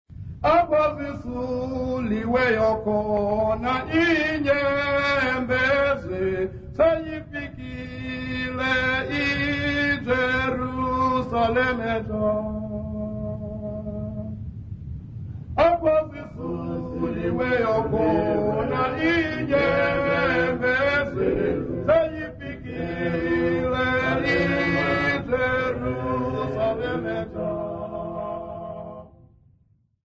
Workshop participants
Church music South Africa
Hymns, Xhosa South Africa
Folk music South Africa
field recordings
Xhosa composition workshop